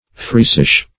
Meaning of friesish. friesish synonyms, pronunciation, spelling and more from Free Dictionary.
friesish - definition of friesish - synonyms, pronunciation, spelling from Free Dictionary Search Result for " friesish" : The Collaborative International Dictionary of English v.0.48: Friesish \Fries"ish\, a. Friesic.